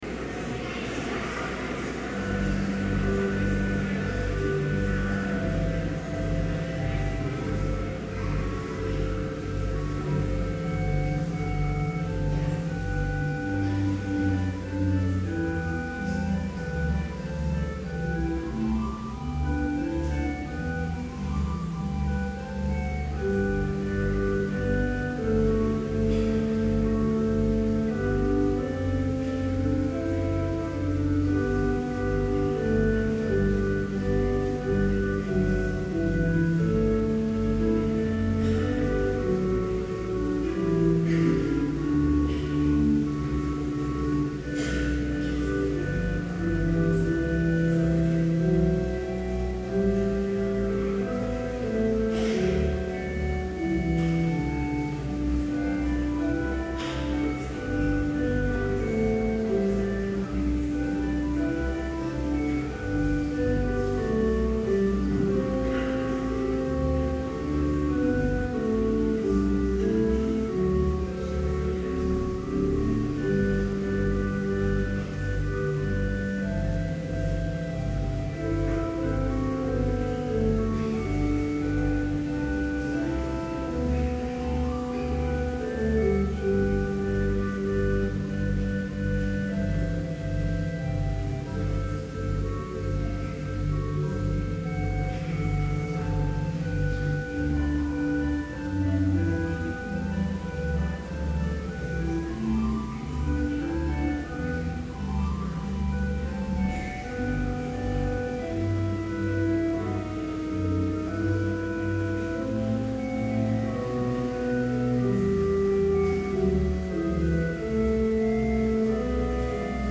Complete service audio for Commencment Vespers - May 12, 2011